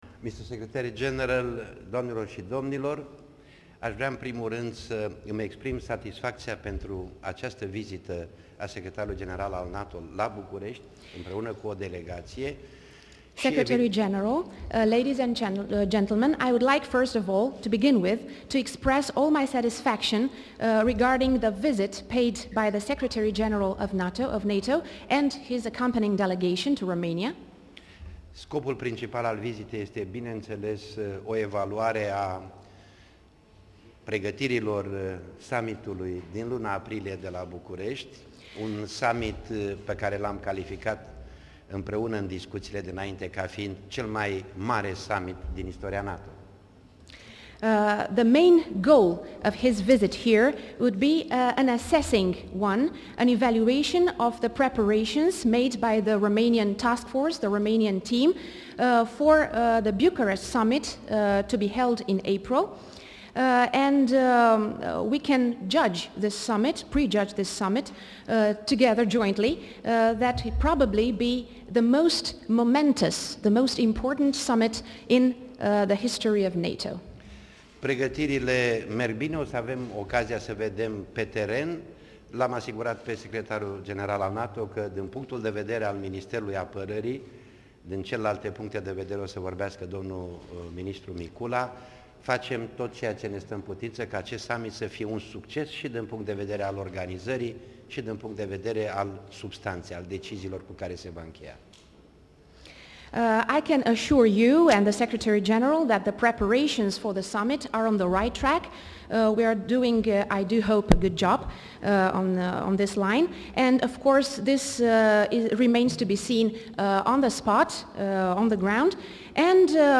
Declarația Ministrului Apărării